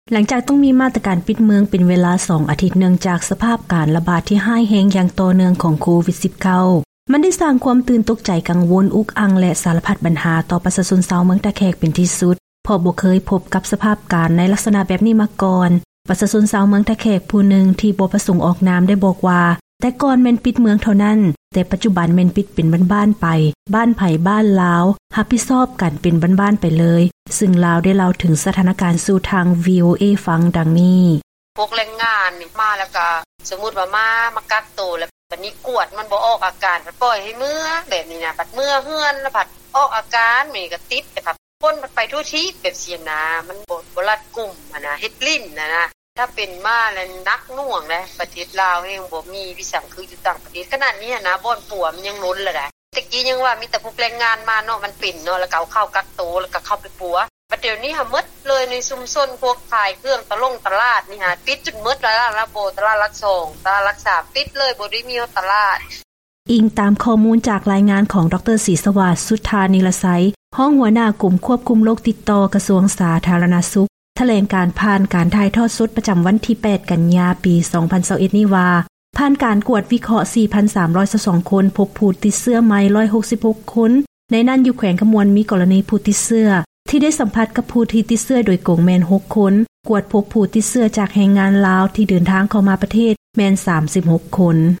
ເຊີນຟັງ ການສໍາພາດປະຊາຊົນຜູ້ນຶ່ງທີ່ອາໄສຢູ່ເມືອງທ່າແຂກ